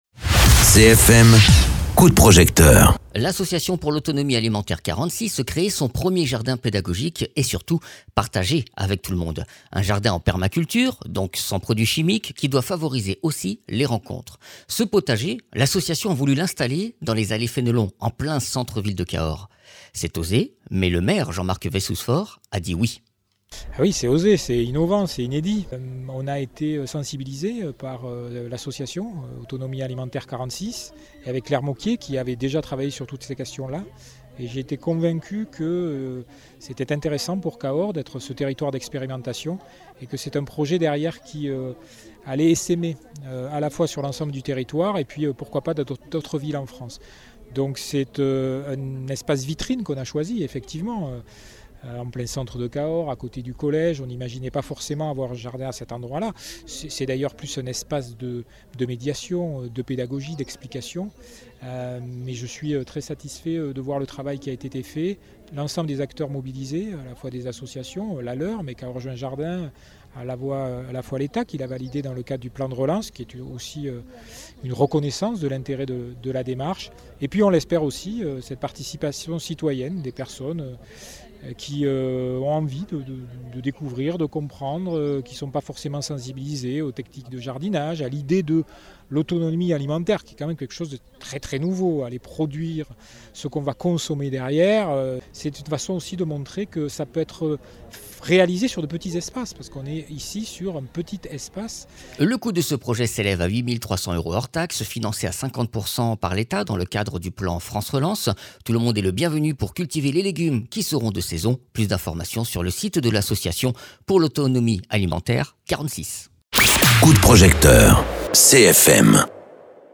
Interviews
Invité(s) : Jean Marc Vayssouze Faure, Maire de Cahors